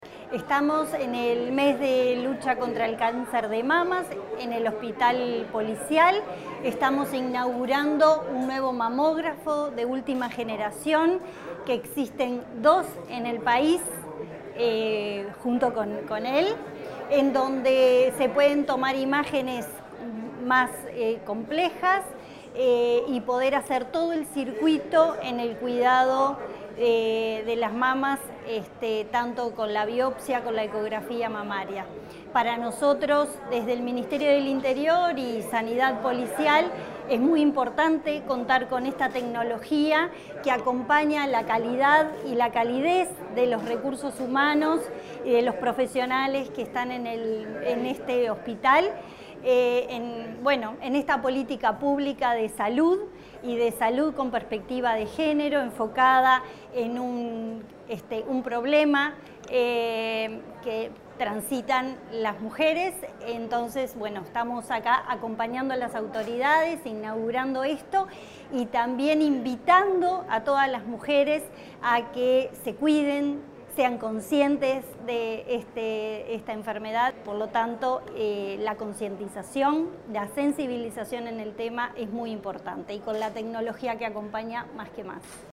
Palabras de la subsecretaria del Interior, Gabriela Valverde
Palabras de la subsecretaria del Interior, Gabriela Valverde 31/10/2025 Compartir Facebook X Copiar enlace WhatsApp LinkedIn Durante la inauguración de un mamógrafo y mejoras edilicias en el Hospital Policial, la subsecretaria del Interior, Gabriela Valverde, destacó la importancia de invertir en tecnología y espacios que fortalezcan la atención médica.